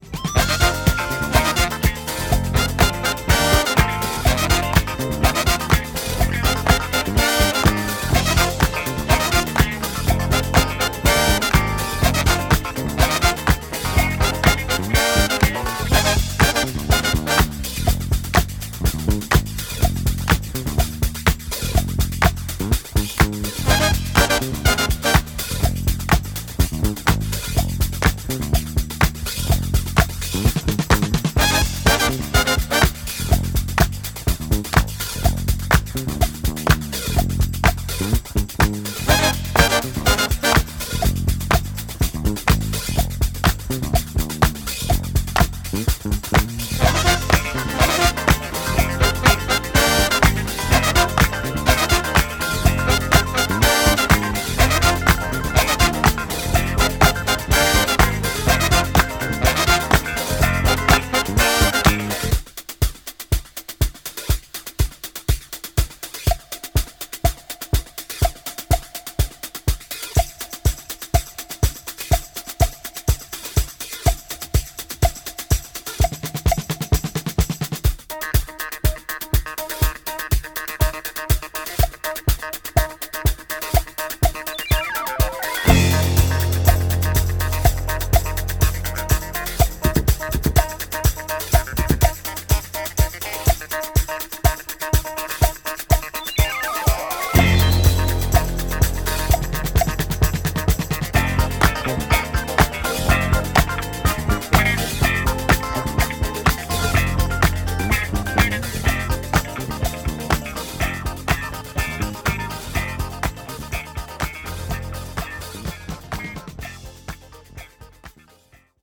Fully licensed and remastered from the original tapes.